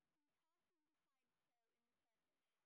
sp19_street_snr20.wav